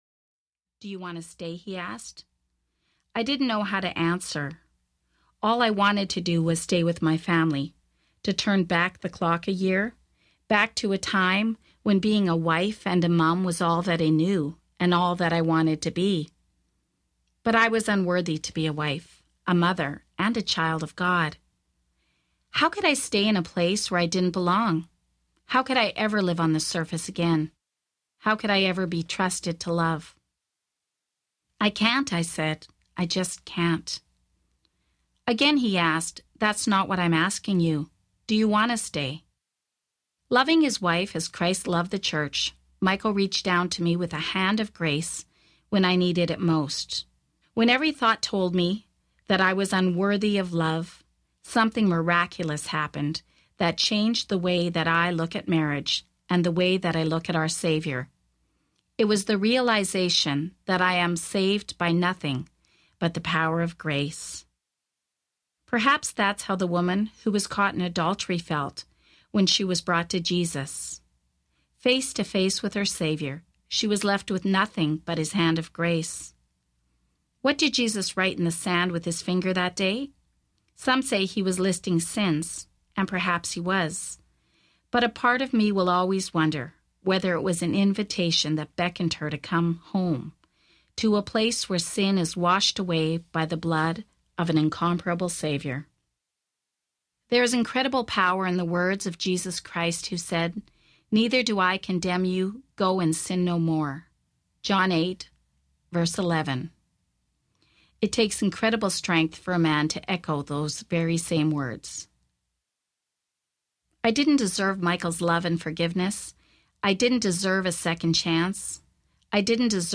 Messy, Beautiful Love Audiobook
4.8 Hrs. – Unabridged